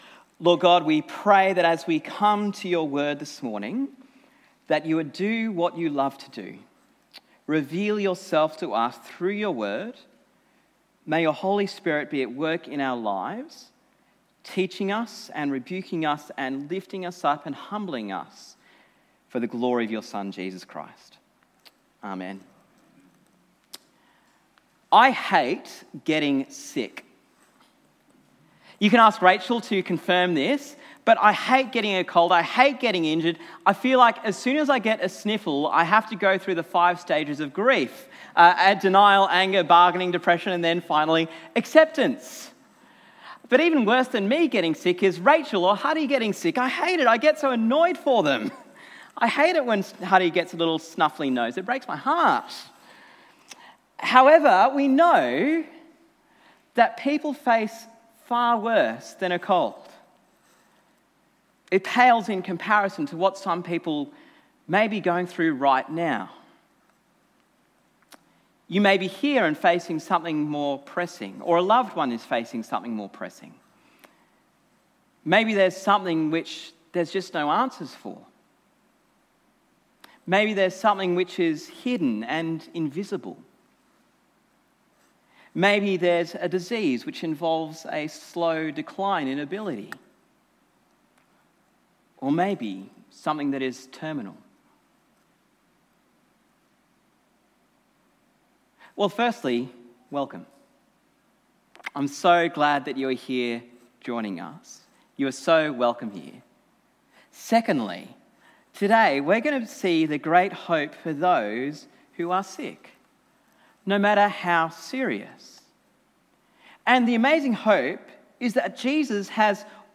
Sermon on Mark 2:1-12